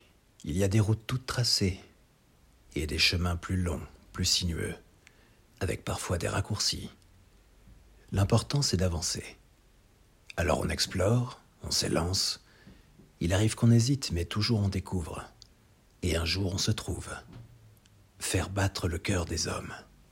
Pub voix off